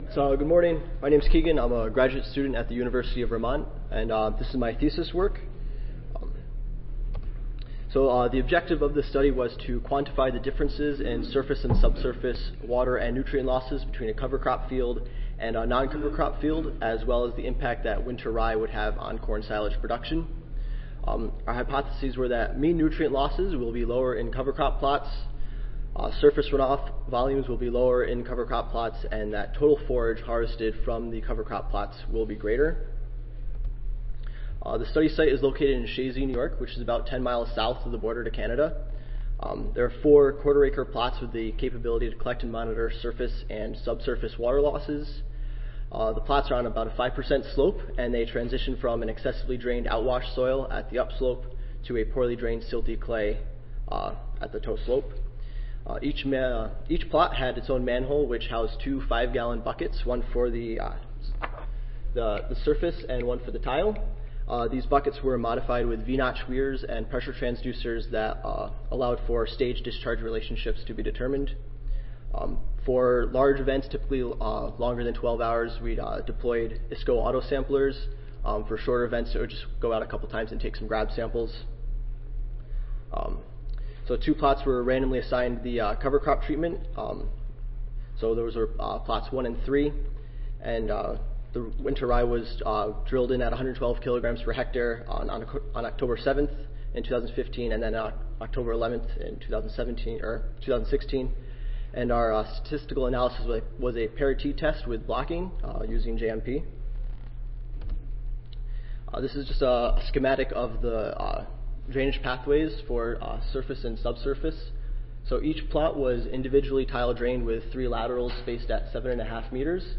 NY Audio File Recorded Presentation Abstract